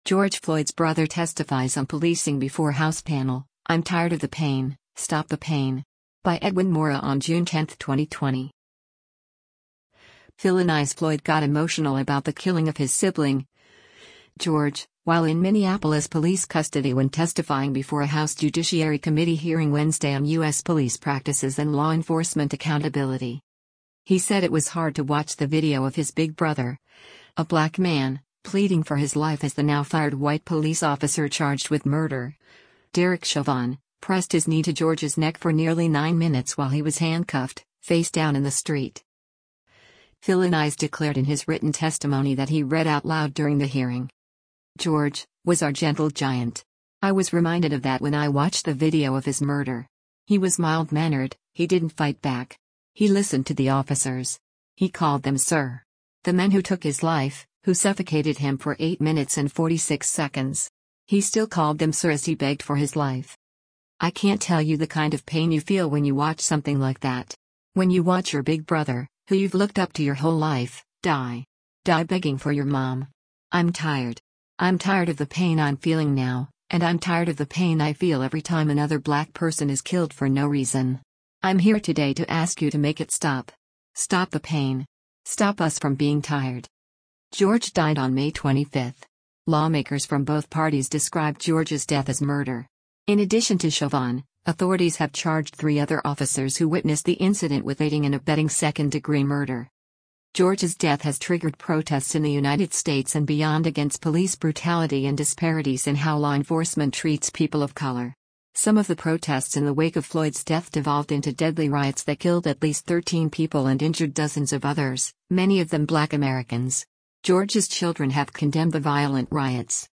Philonise Floyd Testifies
House Judiciary Committee
Philonise Floyd got emotional about the killing of his sibling, George, while in Minneapolis police custody when testifying before a House Judiciary Committee hearing Wednesday on U.S. police practices and law enforcement accountability.
Philonise got emotional and fought back tears as he read the end of his written testimony.